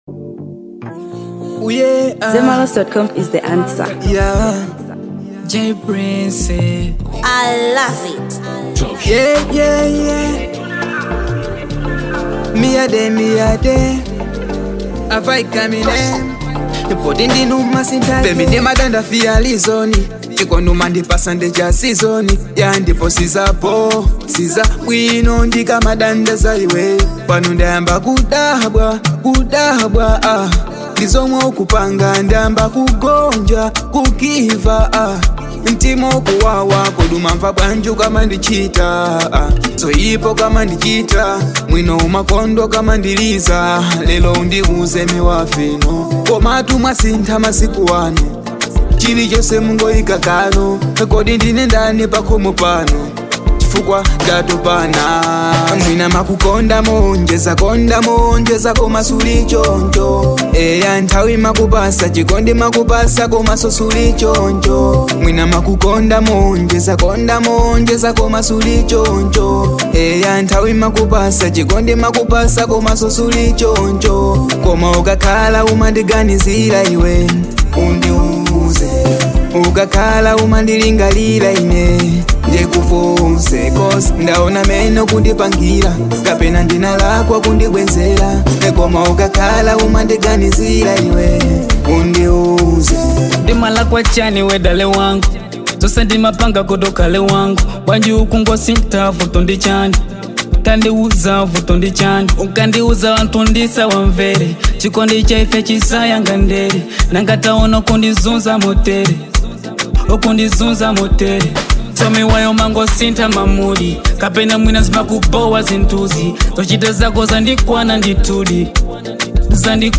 Afrobeats • 2025-09-05